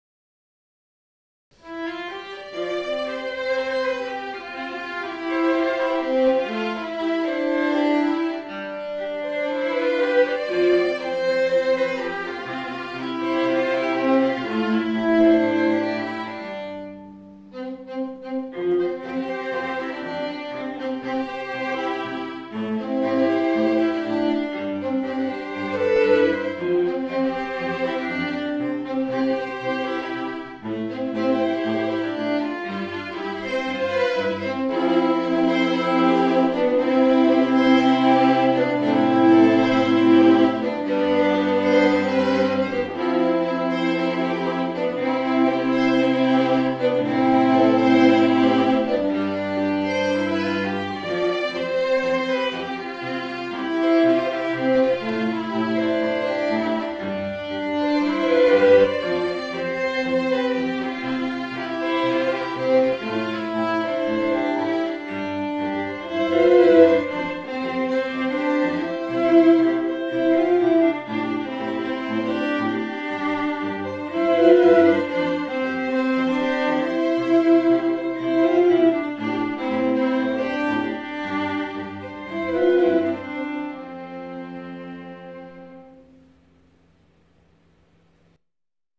3. Quartet comprising two violins, viola and ‘cello, but…
1. contemporary / pop